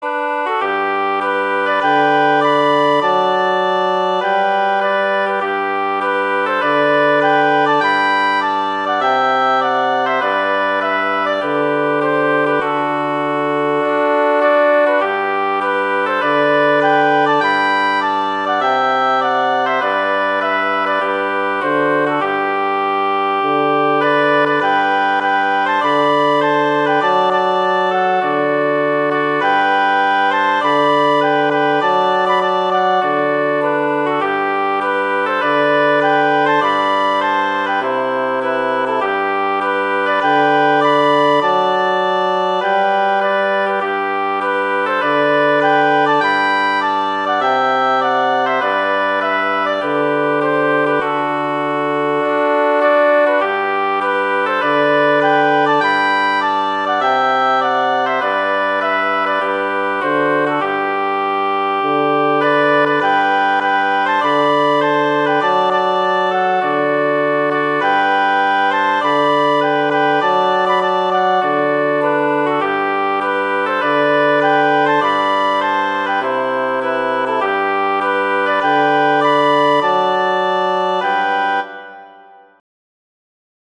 Instrumentation: wind trio
traditional, irish
G major
♩=100 BPM
flute:
oboe:
bassoon: